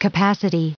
133_capacity.ogg